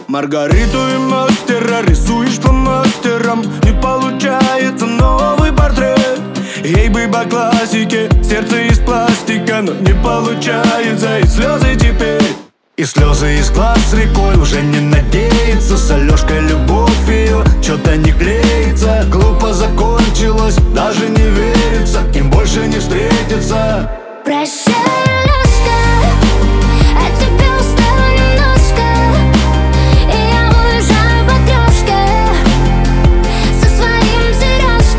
Жанр: Русская поп-музыка / Поп / Русские